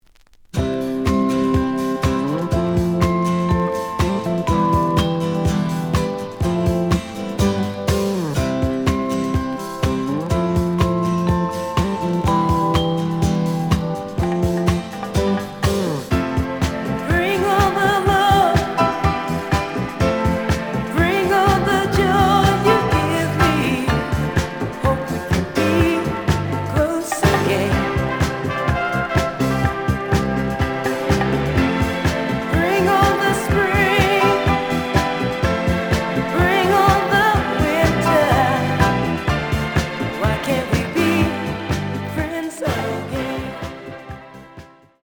(Mono)
The audio sample is recorded from the actual item.
●Genre: Disco